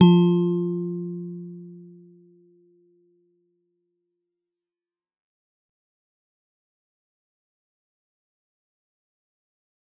G_Musicbox-F3-f.wav